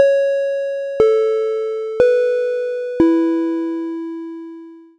4-tone chime WESTMINSTER
bell chime ding microphone pa ping ring sound effect free sound royalty free Sound Effects